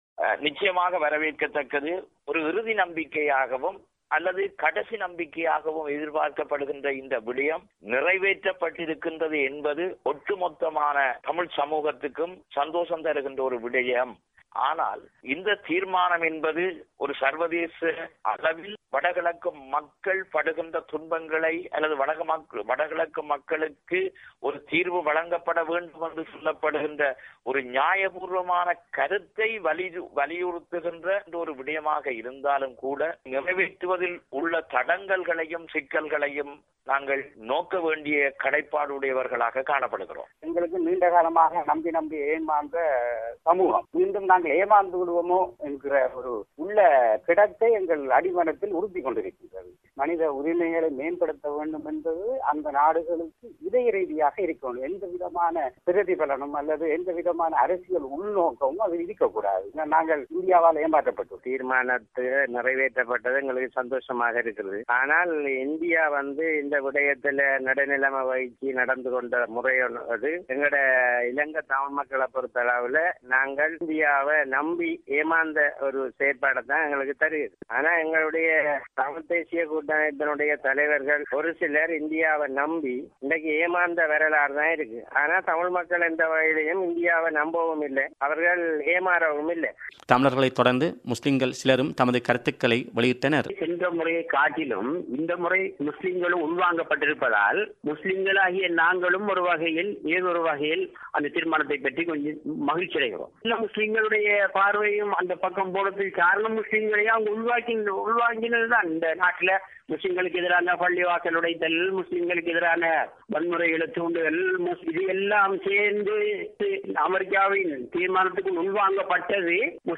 ஐநா மனித உரிமைகள் ஆணைக்குழுவில் இலங்கை குறித்து கொண்டுவரப்பட்ட தீர்மானம் பற்றி கிழக்கு மாகாண மக்களின் கருத்துக்களை இங்கு கேட்கலாம்.